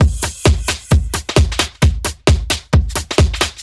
Index of /musicradar/uk-garage-samples/132bpm Lines n Loops/Beats
GA_BeatDMod132-01.wav